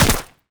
character_shot.wav